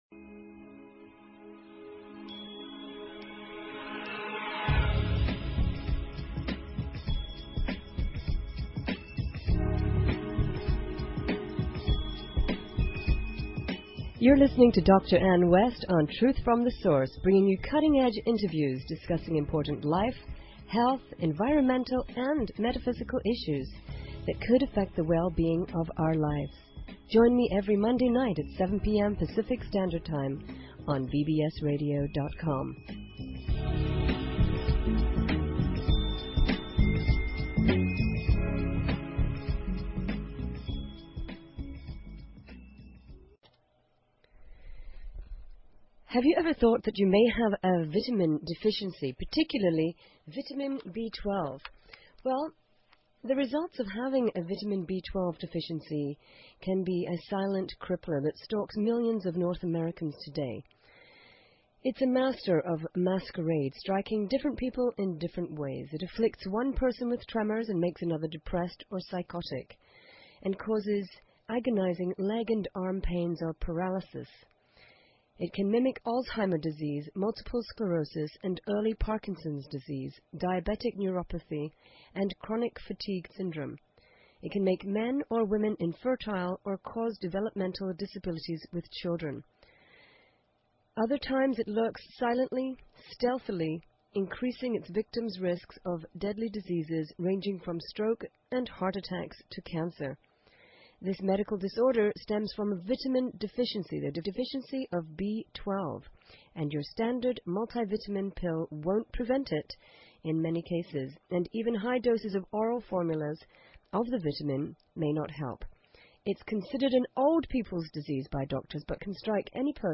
Talk Show Episode, Audio Podcast, Truth_From_Source and Courtesy of BBS Radio on , show guests , about , categorized as